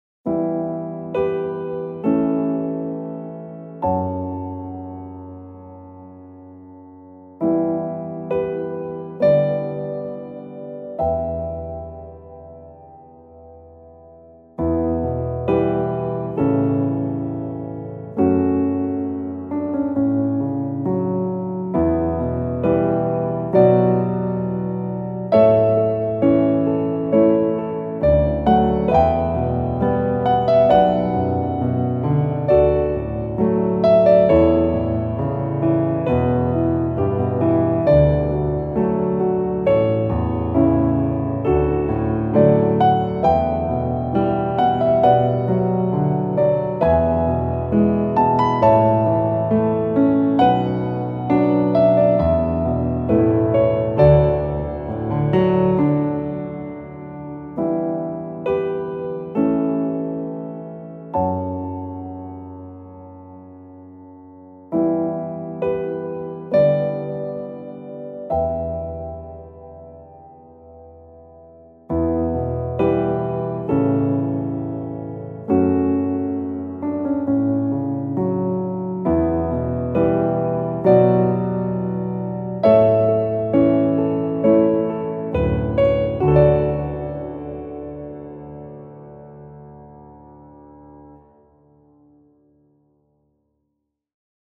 静かな雰囲気のピアノBGMです。